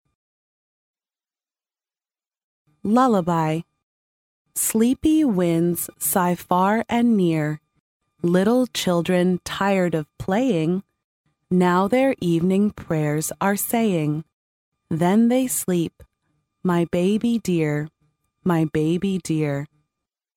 幼儿英语童谣朗读(MP3+中英字幕) 第1期:摇篮曲 听力文件下载—在线英语听力室